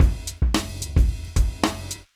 110LOOP B9-L.wav